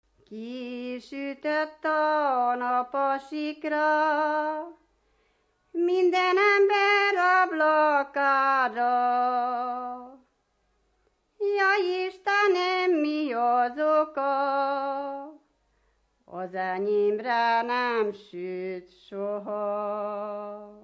Moldva és Bukovina - Bukovina - Hadikfalva
Kitelepülés helye: Kéty
Stílus: 3. Pszalmodizáló stílusú dallamok